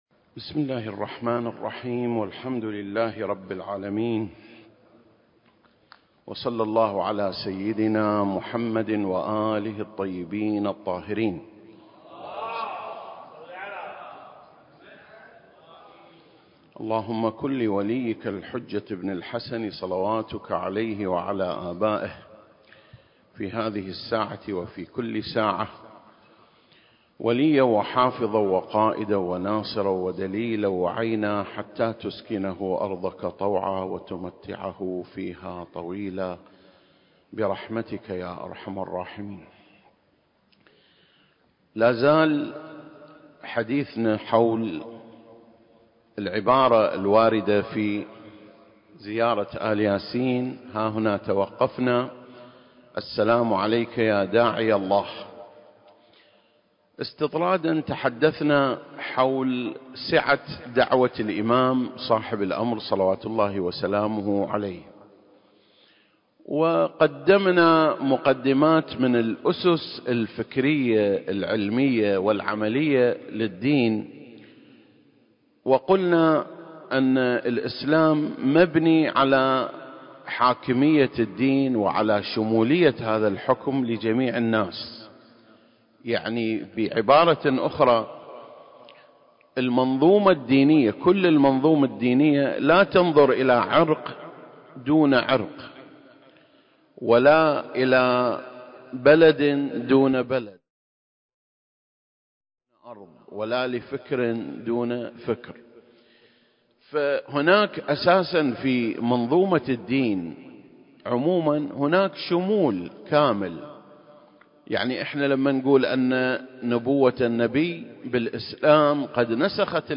سلسلة: شرح زيارة آل ياسين (29) - سعة الدعوة المهدوية (3) المكان: مسجد مقامس - الكويت التاريخ: 2021